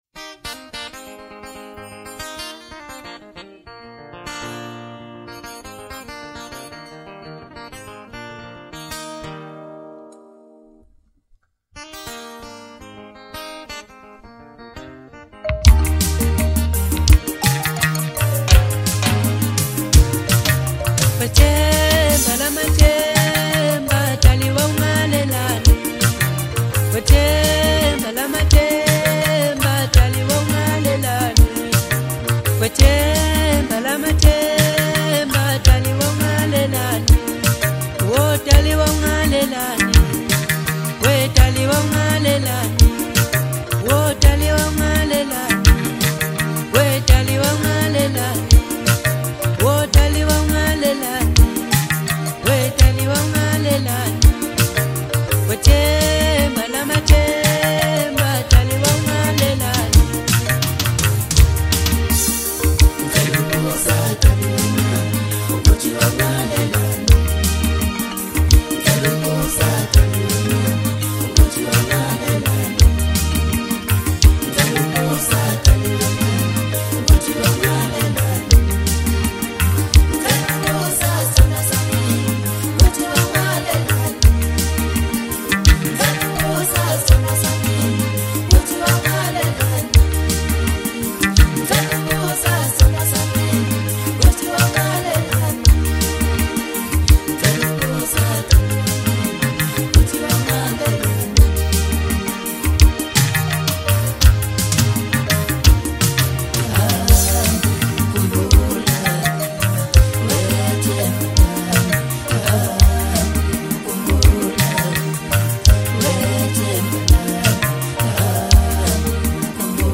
Home » Maskandi » DJ Mix » Hip Hop